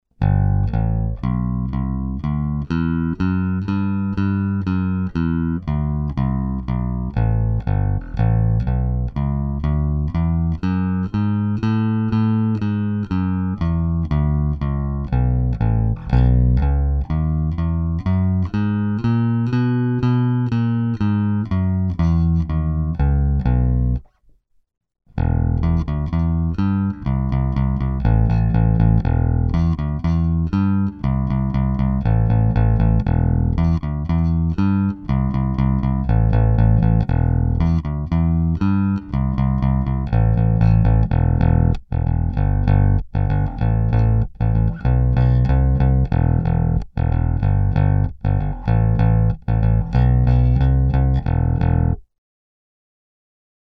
Proč při stejném dohmatu, když hraju trsátkem Warwick drnčí o pražce a Squier je v pohodě.
Tak jsem schválně nahrál ukázku, - jestli poznáte, co je warw a co squier .